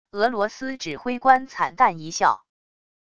俄罗斯指挥官惨淡一笑wav音频